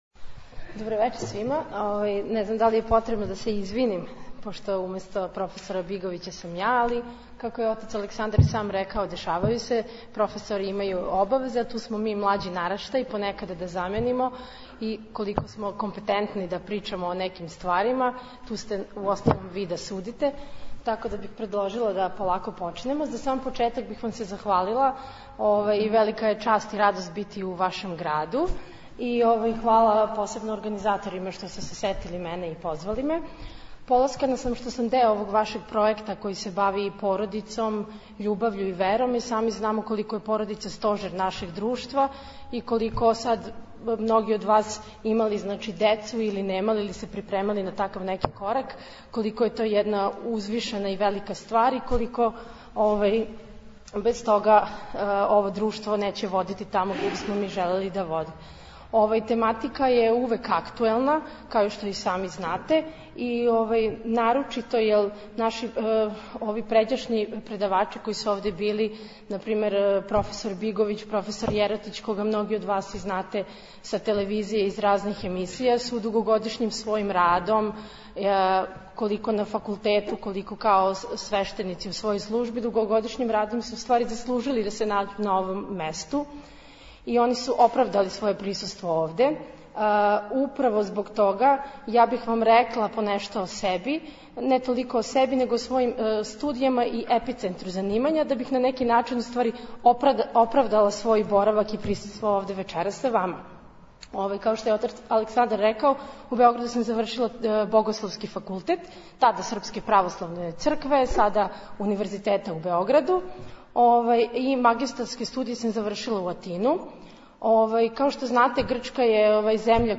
Звучни запис предавања